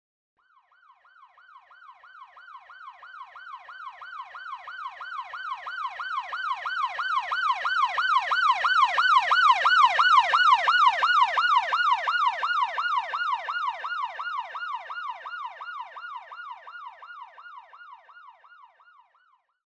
Звуки полицейской машины
На этой странице собрана коллекция звуков полицейских машин в разных вариациях: от стандартных сирен до экстренных сигналов и шумов двигателя.